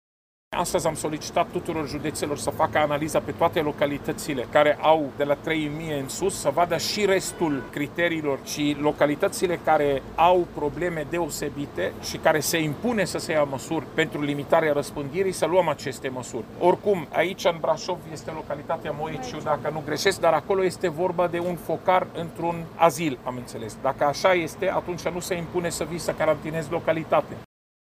Informaţia a fost confirmată de conducerea Direcţiei de Sănătate Publică Braşov, la solicitarea secretarului de stat Raed Arafat, prezent, astăzi, la Braşov, pentru a verifica stadiul lucrărilor de la unitatea modulară ATI din curtea Spitalului Judeţean: